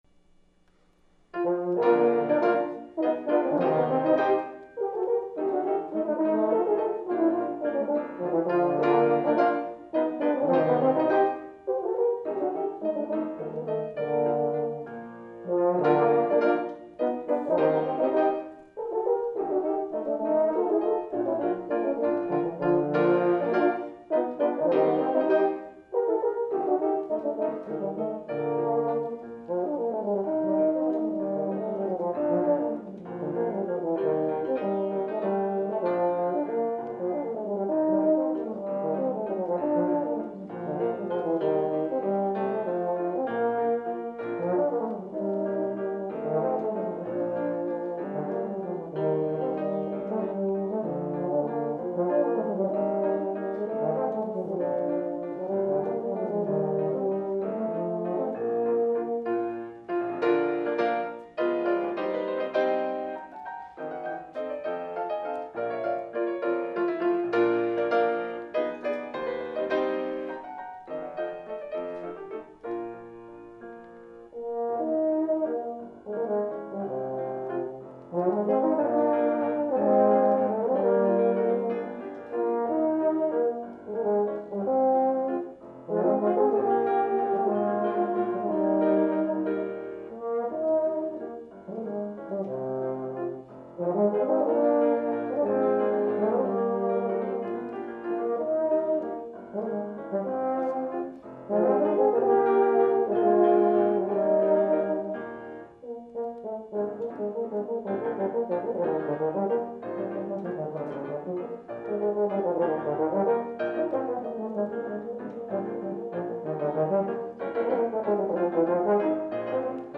For Euphonium Duet
with Piano.